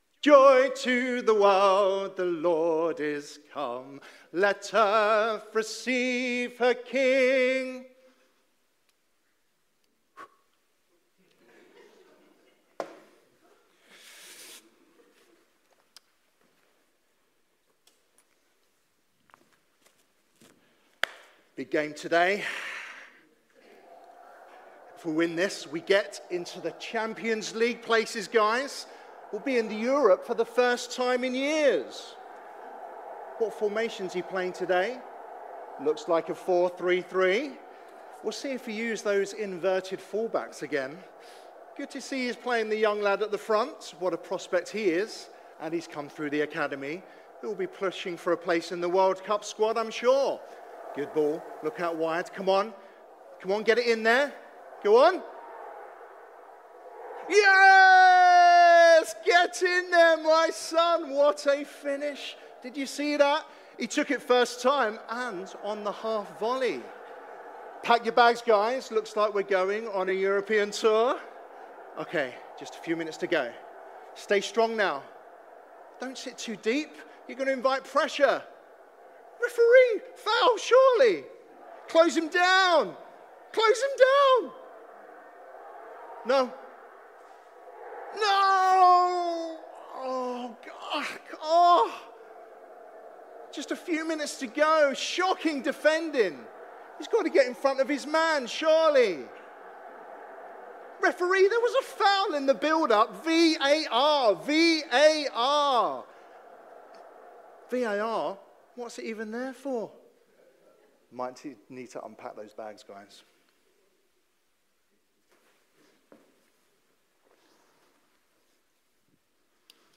Preacher
Sounds of the Season Passage: Romans 5:1–11 Service Type: Sunday Morning « Sounds of the Season